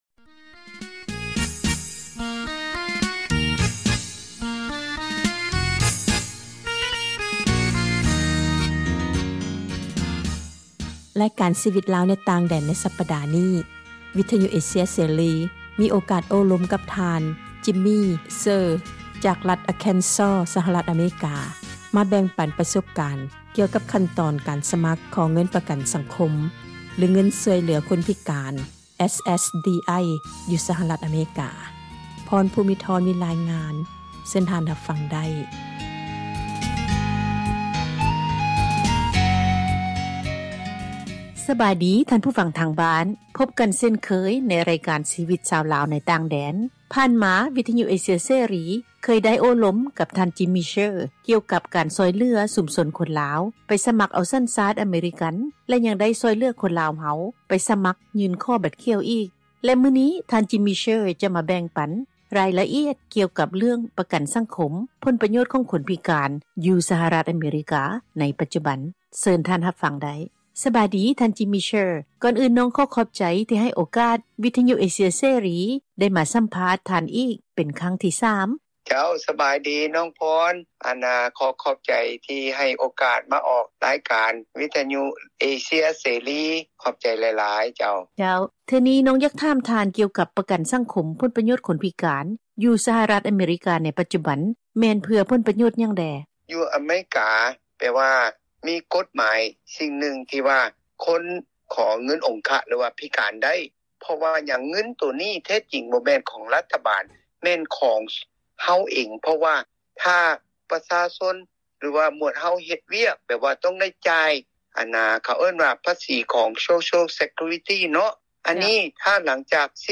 ຣາຍການ ຊີວິດຊາວລາວ ໃນຕ່າງແດນ ໃນສັປດານີ້ ວິທຍຸ ອເຊັຽເສຣີ ໄດ້ມີໂອກາດມາ ໂອລົມ